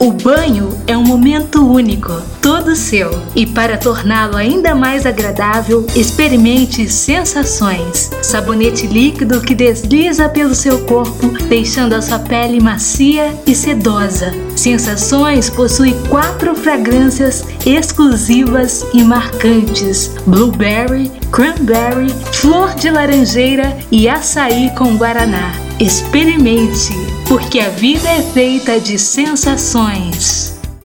Já que o trabalho passou e não vou quebrar a surpresa da apresentação que foi ontem à noite, tomei a liberdade de postar uma peça publicitária, o chamado “spot” para rádio, com duração de 30 segundos do sabonete líquido Sensações… rs
Nem passei o texto escrito pra ele e logo gravei de uma vez só: Bummmm! rssss E foi uma só vez mesmo porque a minha mãe começou a preparar o jantar e as panelinhas começaram a fazer ruído na cozinha, e, como não tenho estúdio com “casca de ovo” rssss , ou, tecnicamente, sonex, não consigo deixar o ambiente isolado acusticamente, no caso aqui, gravo os materiais do blog, na sala de casa, sem mesa de som ou microfone…